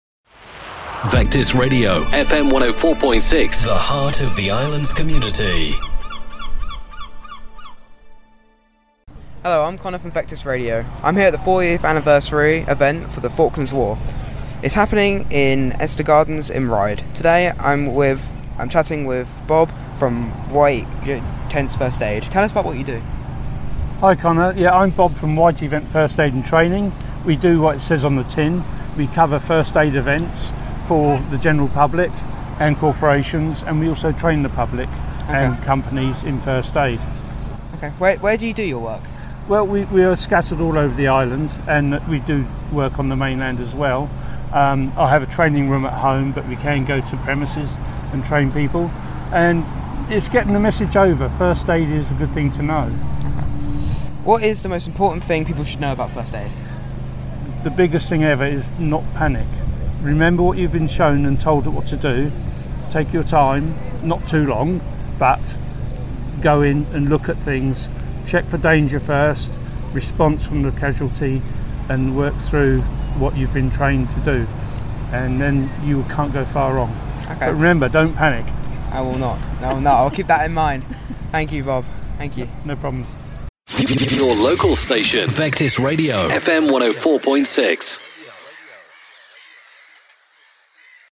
Saturday and Sunday 28th and 29th May 2022 saw an event taking place in Eastern Gardens in Ryde.